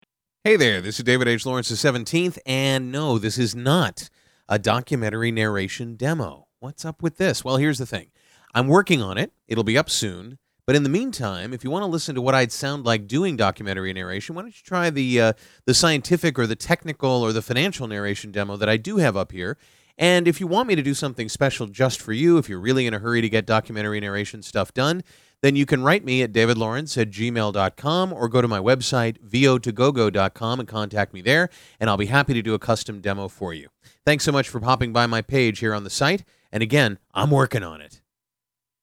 Labeled as such, but really a promo for your other demos in disguise, this humorous approach will still help you attract clients, but lessen some of the pressure to get everything done right now.
fake-doc-narr-demo.mp3